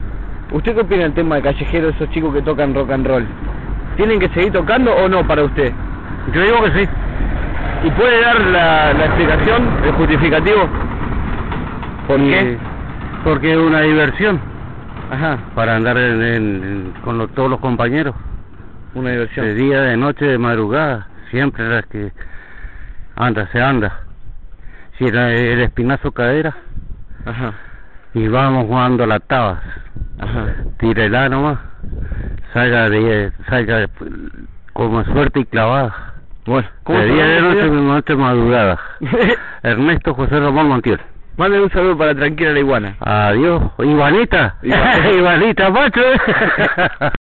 洛菲钢琴
描述：一个小的松散的钢琴和弦进行曲
Tag: 110 bpm Jazz Loops Piano Loops 1.47 MB wav Key : Unknown